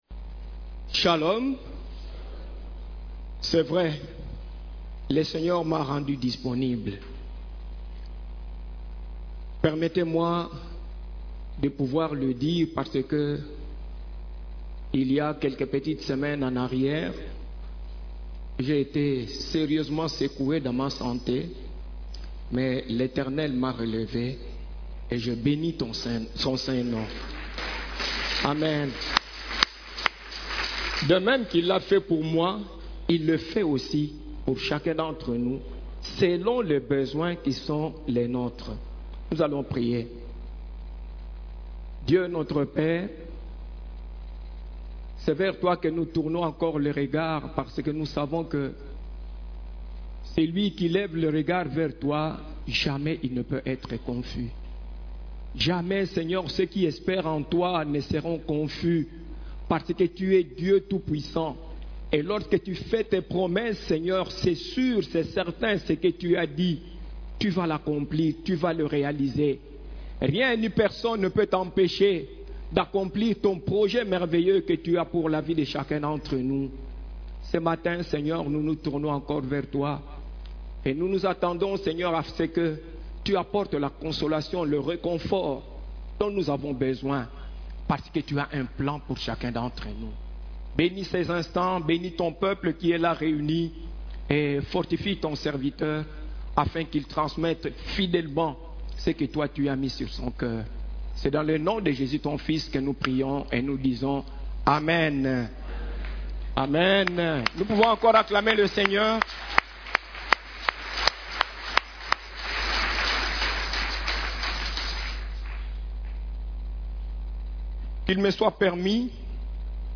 CEF la Borne, Culte du Dimanche, Vaches belles, Vaches laides, Vaches grasses, Vaches minces